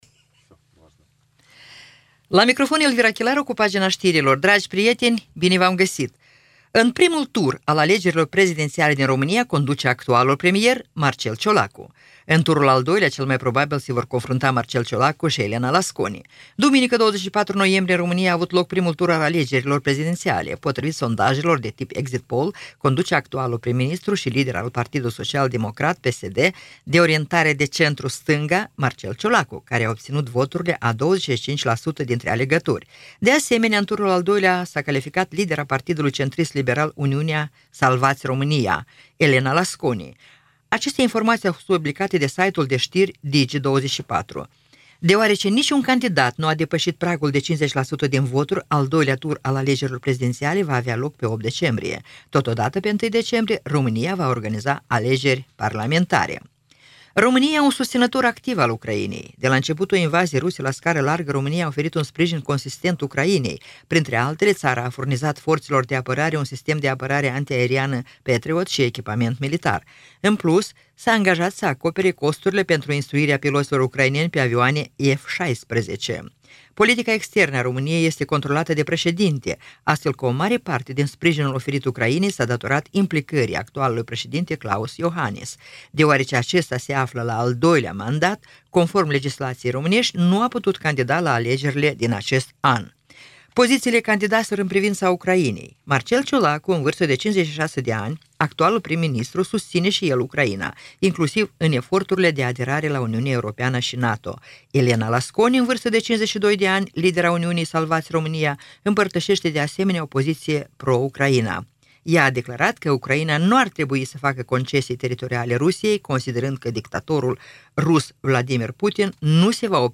Știri Radio Ujgorod – 25.11.2024